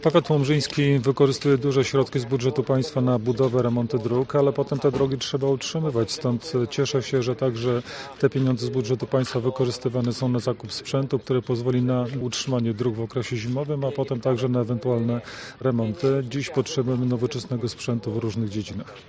Wiceminister Edukacji i Nauki, Dariusz Piontkowski dodał, że o drogi w powiecie trzeba dbać: